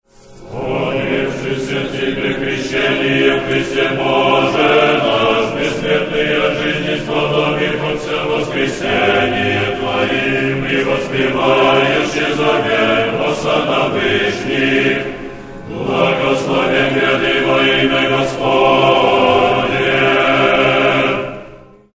Тропарь Входа Господня в Иерусалим